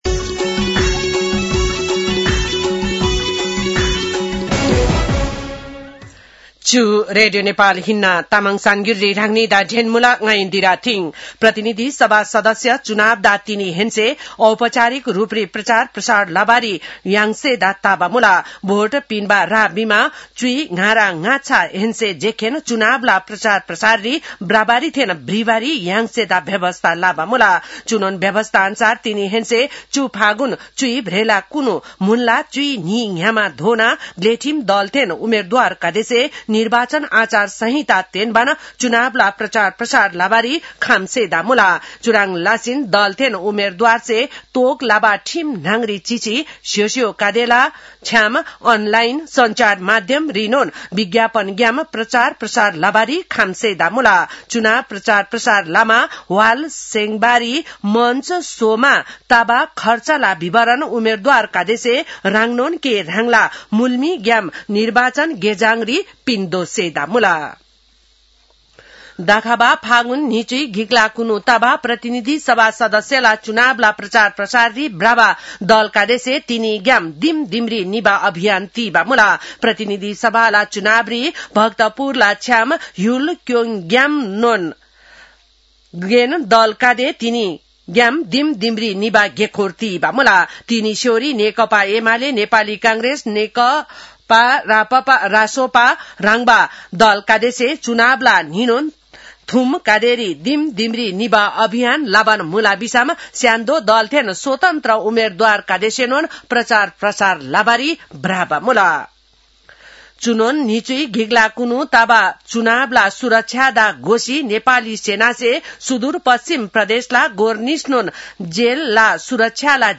तामाङ भाषाको समाचार : ४ फागुन , २०८२